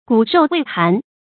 骨肉未寒 注音： ㄍㄨˇ ㄖㄡˋ ㄨㄟˋ ㄏㄢˊ 讀音讀法： 意思解釋： 骨肉尚未冷透。